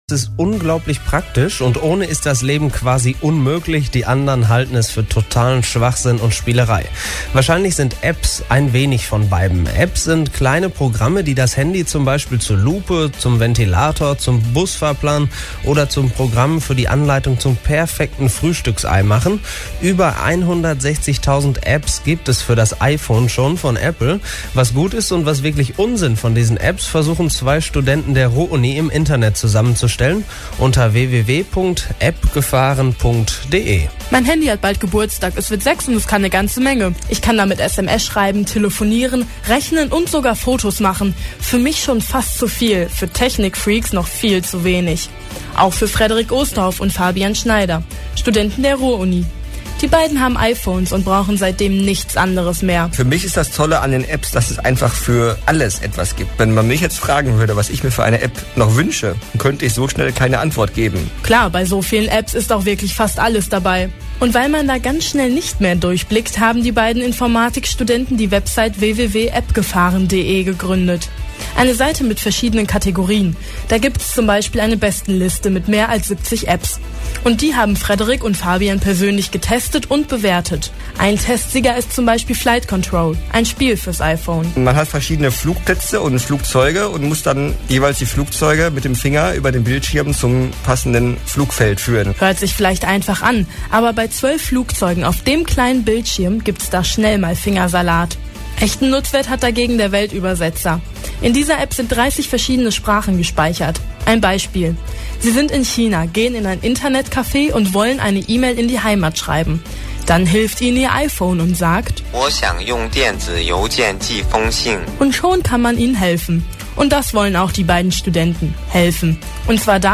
In der vergangenen Woche waren wir im Studio von Radio Bochum zu Gast und haben ein wenig über das iPhone und die vielen tausenden Applikationen berichtet. Am Dienstag war der Beitrag dann im Lokalradio zu hören.